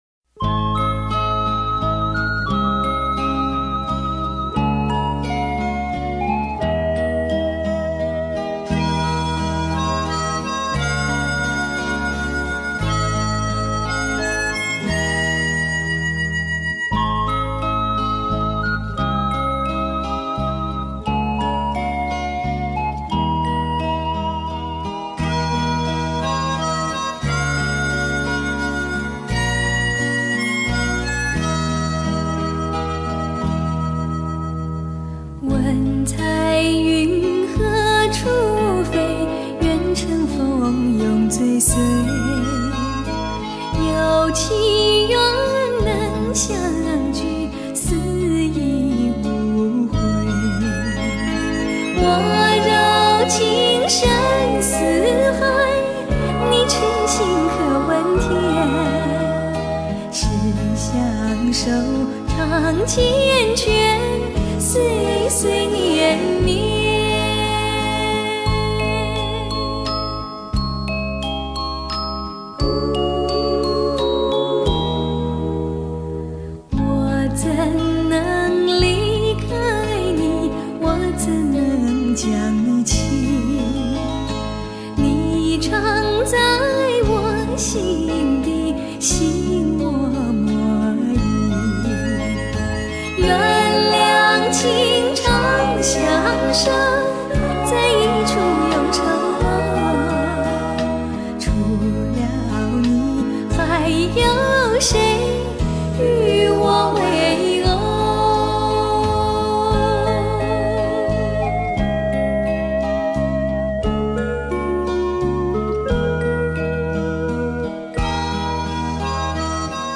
MADE IN JAPAN 保持最原音效果